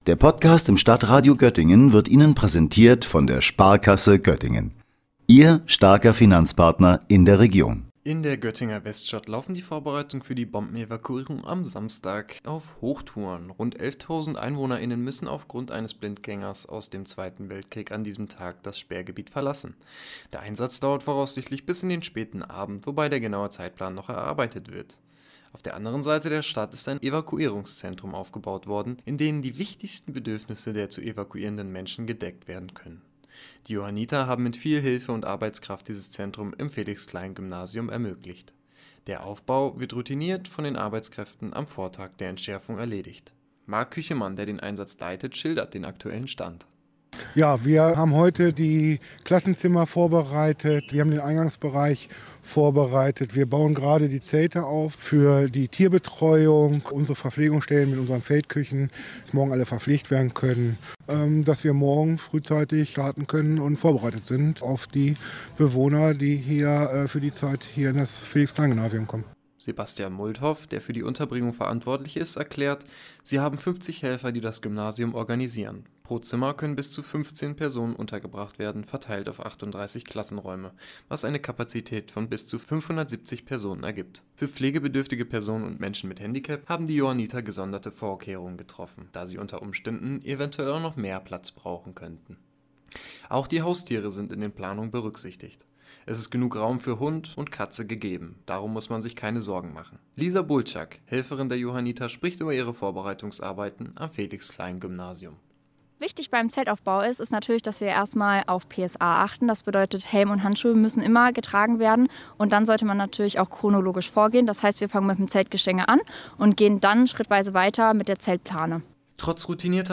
Die Schule wurde als Evakuierungszentrum vorübergehend umfunktioniert.